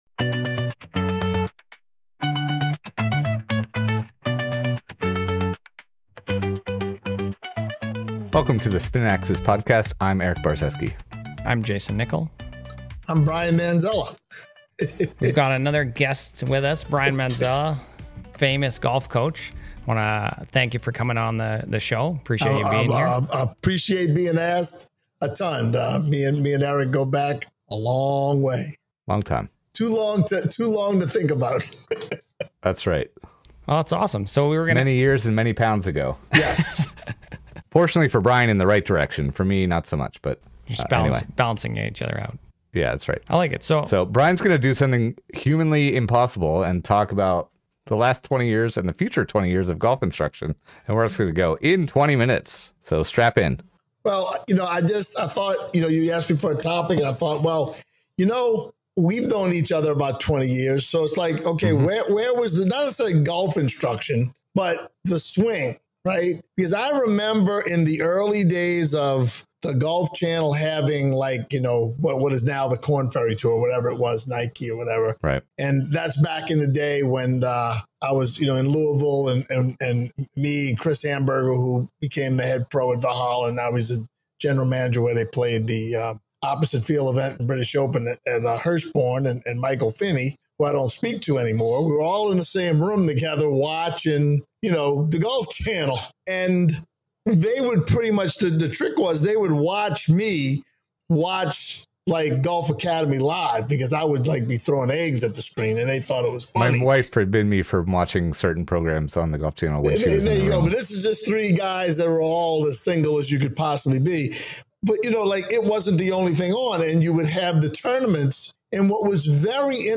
In part one of our two-part conversation, we take a look back at how golf instruction has evolved.